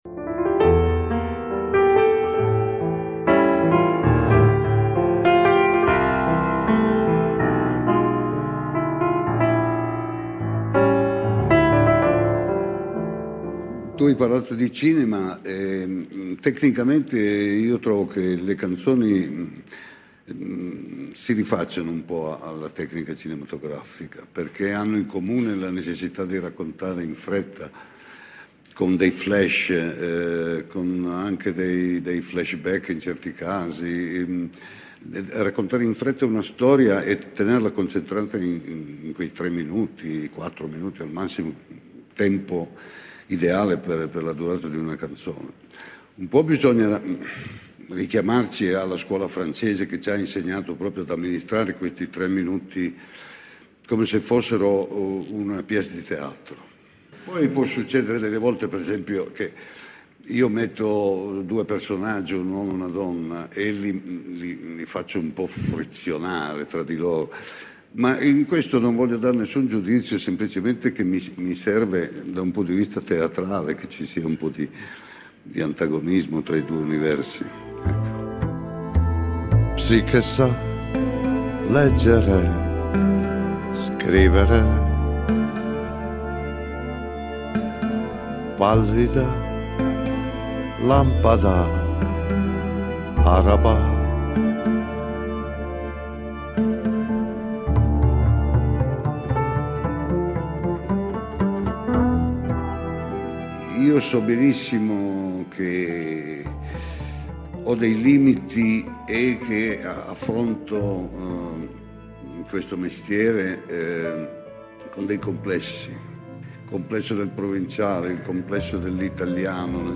abbiamo impaginato qualche estratto assai curioso dell’intervista con “l’avvocato di Asti”.